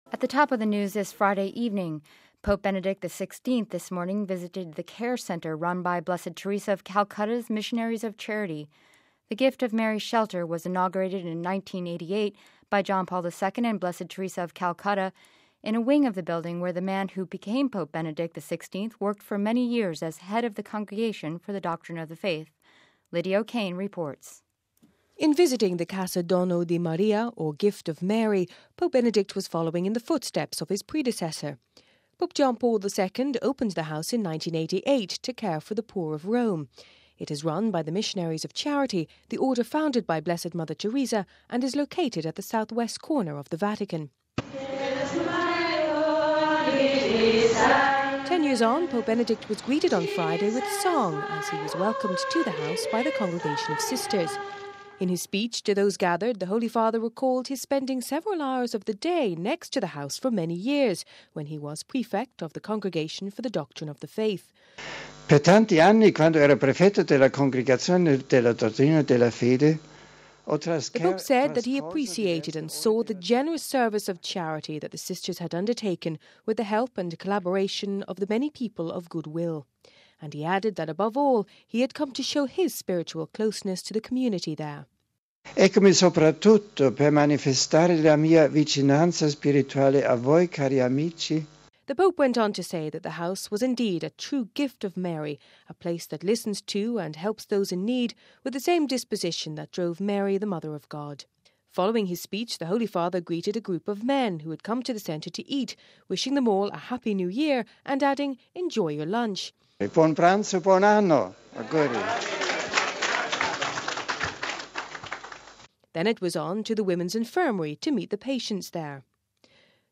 reports on Pope Benedict XVI's visit to the Missionaries' of Charity shelter in the Vatican